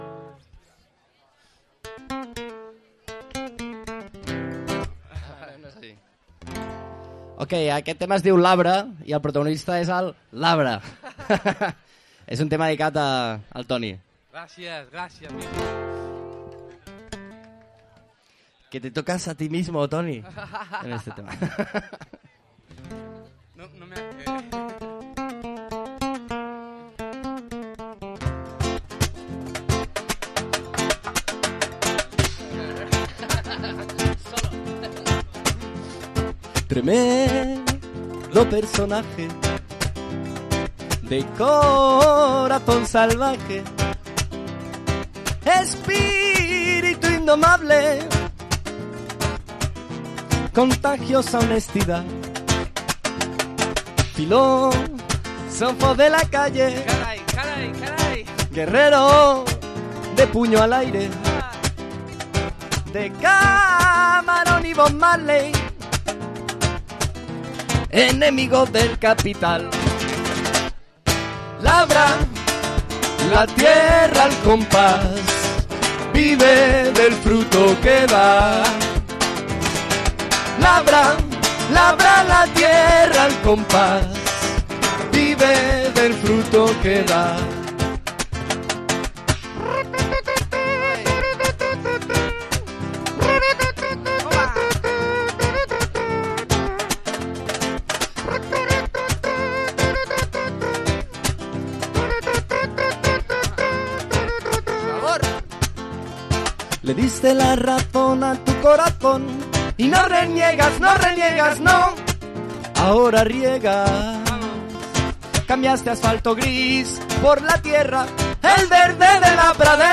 Darrera part de l’emissió en viu que vam fer al PIC de Can Masdeu per tancar les celebracions de XXV anys contrabandistes, compartint micros amb part del programa germà i també domingero Espiadimonis.